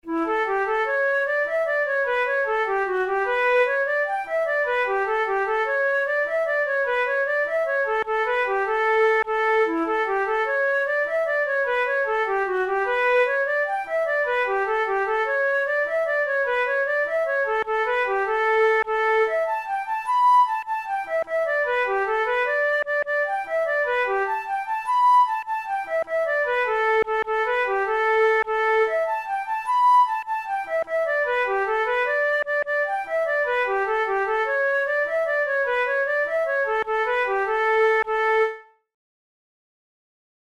Traditional Irish jig
Categories: Jigs Traditional/Folk Difficulty: easy